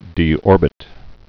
(dē-ôrbĭt)